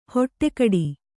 ♪ hoṭṭe kaḍi